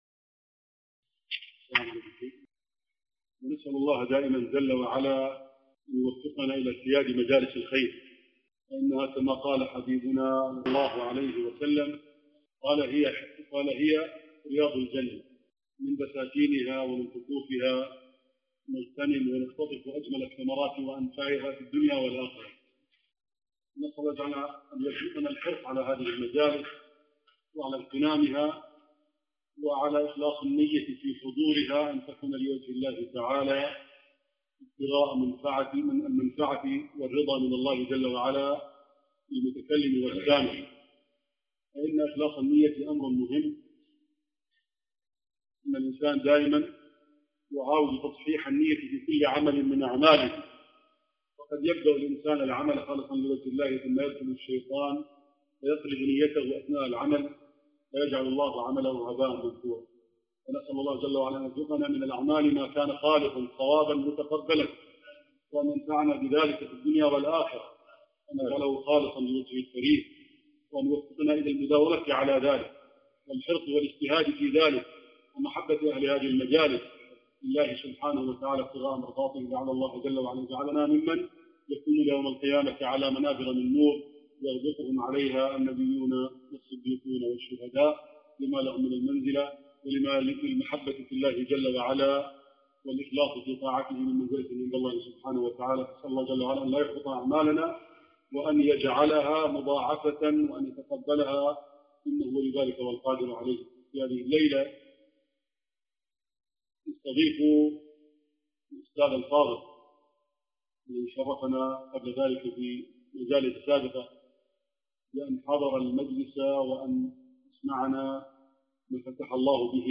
محاضرة صوتية